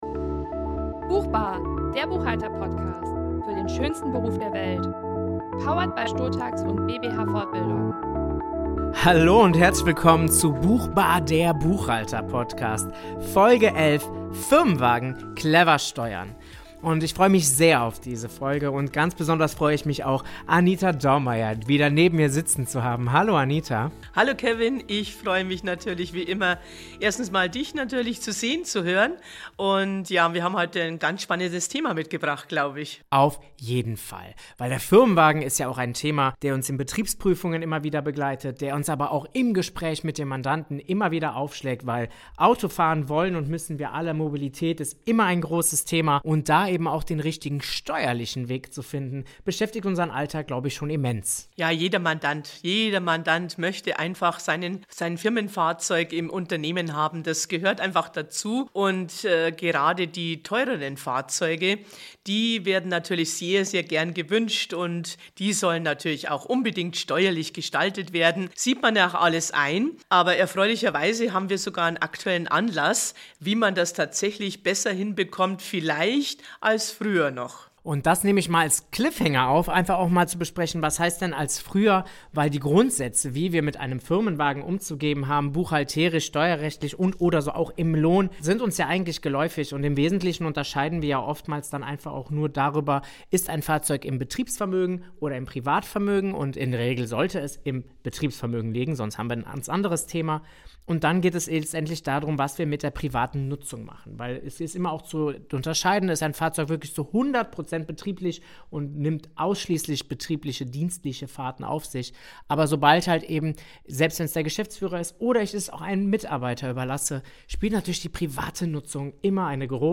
Dabei gibt es einige Voraussetzungen welche das Podcast Duo ausführlich schildert.